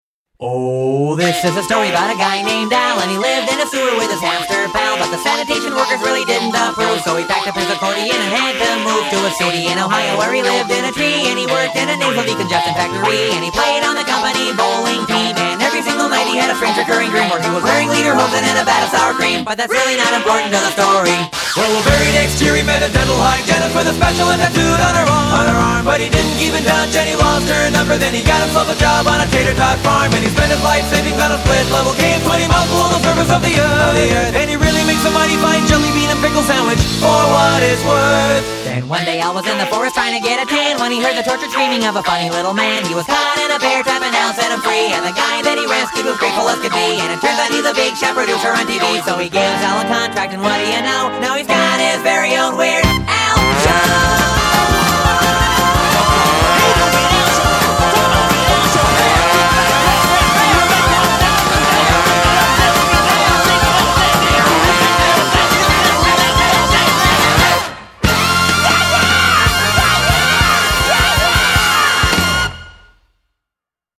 super-wordy